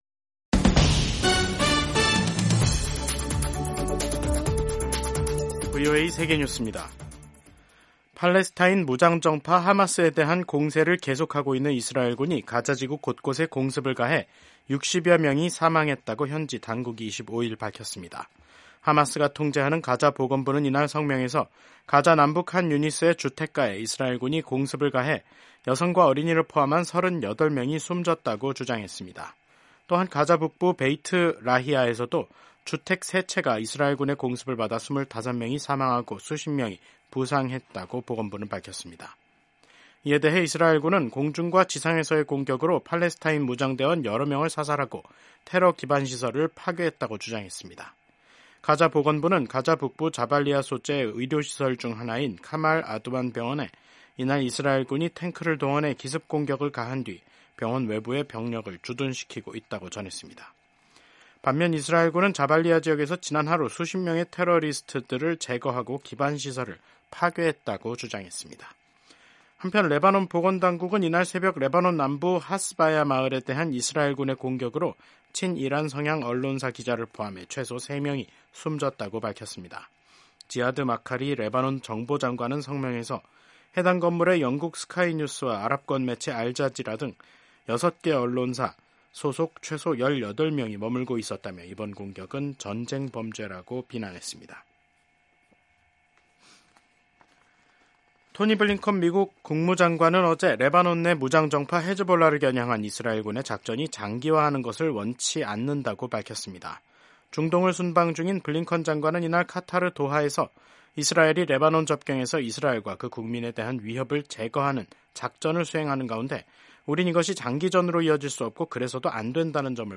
세계 뉴스와 함께 미국의 모든 것을 소개하는 '생방송 여기는 워싱턴입니다', 2024년 10월 25일 저녁 방송입니다. 레바논에서 긴급하게 외교 해법이 필요하다고 토니 블링컨 미국 국무장관이 강조했습니다. 미국 민주당 대선 후보인 카멀라 해리스 부통령이 유명 가수 비욘세와 함께 25일 유세에 나섭니다.